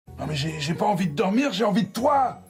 minecraft / sounds / mob / zombie / wood2.ogg